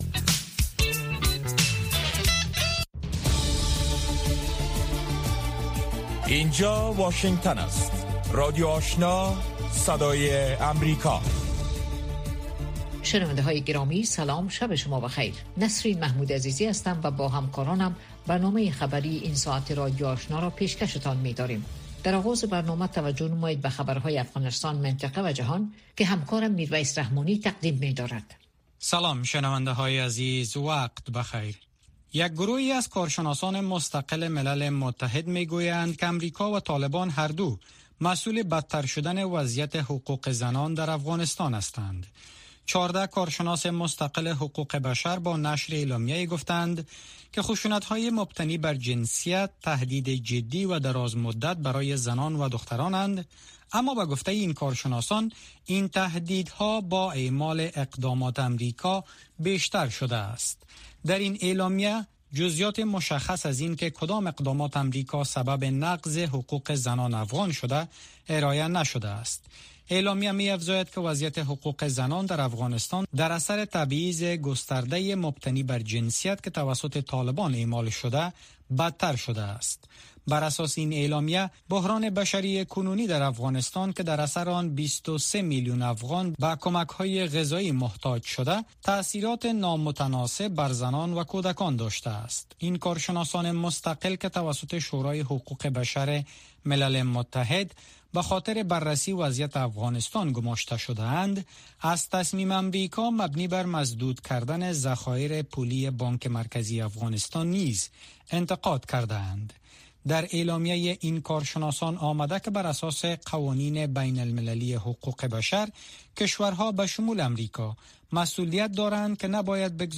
نخستین برنامه خبری شب